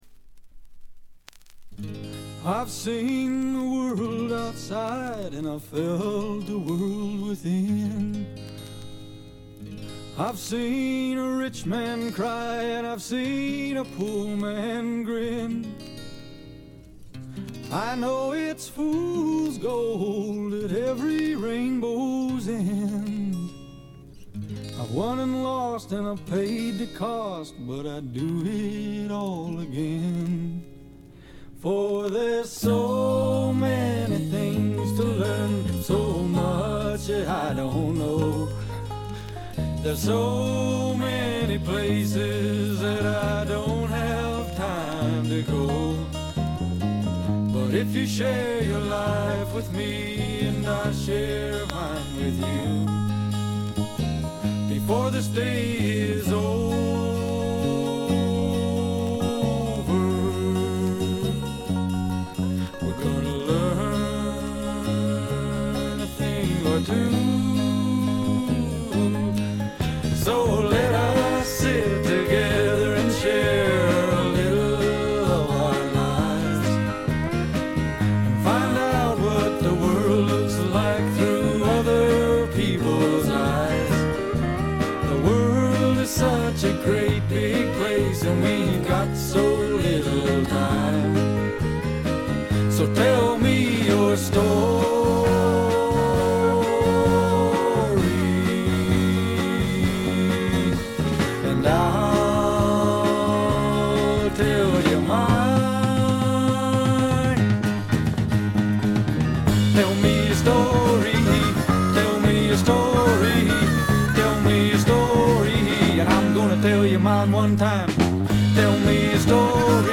カナディアンらしくカントリー臭とか土臭さはあまり感じません。
試聴曲は現品からの取り込み音源です。